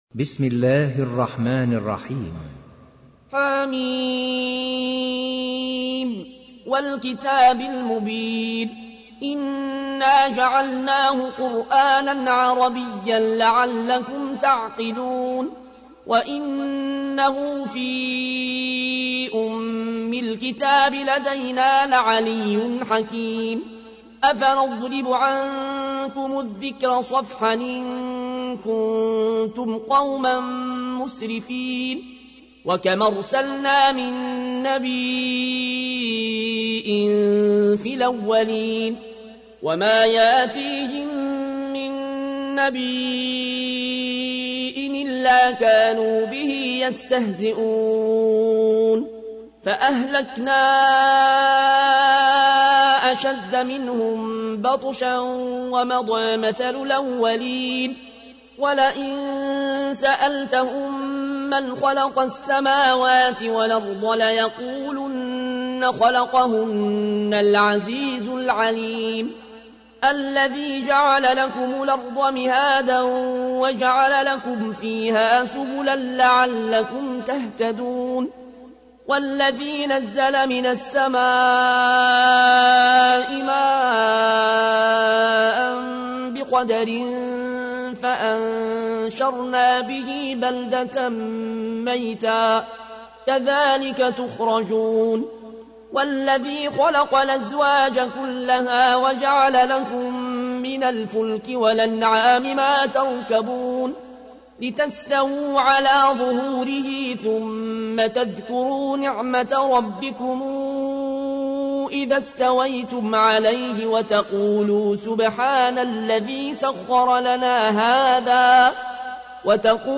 تحميل : 43. سورة الزخرف / القارئ العيون الكوشي / القرآن الكريم / موقع يا حسين